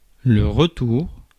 Ääntäminen
France: IPA: /ʁə.tuʁ/